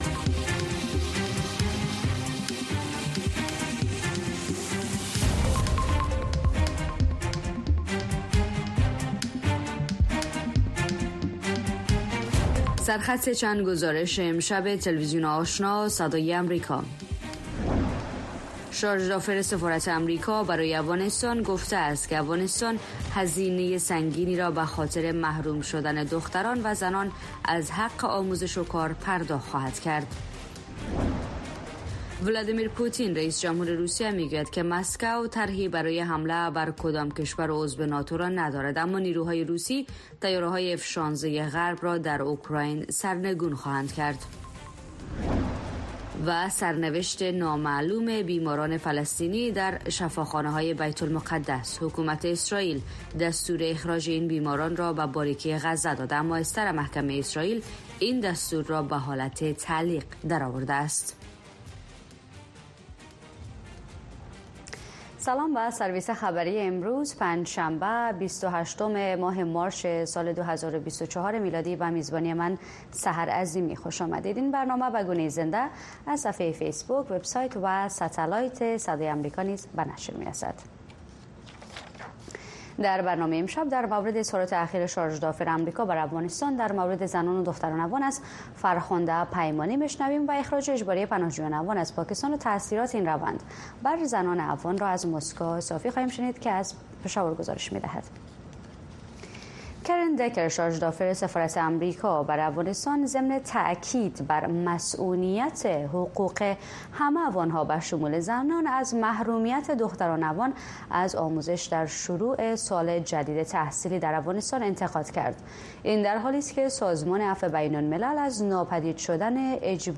تازه‌ترین خبرهای افغانستان، منطقه و جهان، گزارش‌های جالب و معلوماتی از سراسر جهان، مصاحبه‌های مسوولان و صاحب‌نظران، صدای شما و سایر مطالب را در برنامهٔ خبری آشنا از روزهای شنبه تا پنج‌شنبه در رادیو، ماهواره و شبکه های دیجیتلی صدای امریکا دنبال کنید.